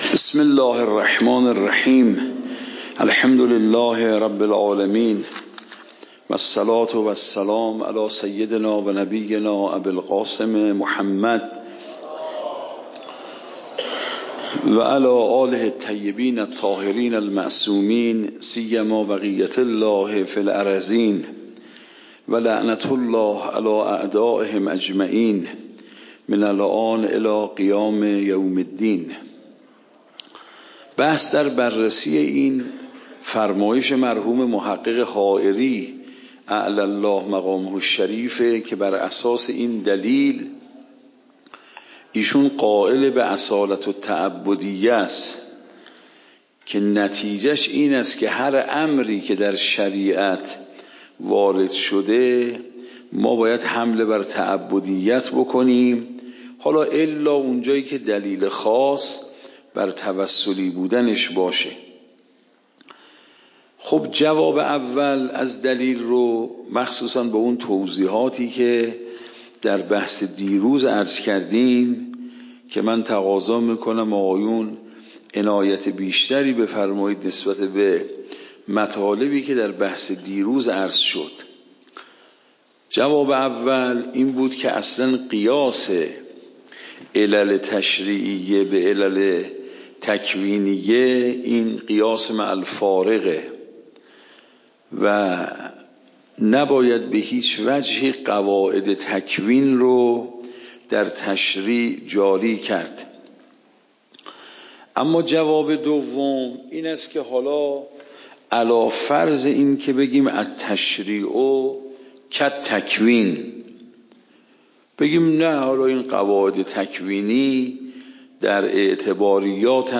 درس بعد تعبدی و توصلی درس قبل تعبدی و توصلی درس بعد درس قبل موضوع: واجب تعبدی و توصلی اصول فقه خارج اصول (دوره دوم) اوامر واجب تعبدی و توصلی تاریخ جلسه : ۱۴۰۴/۲/۱ شماره جلسه : ۸۰ PDF درس صوت درس ۰ ۱,۶۱۴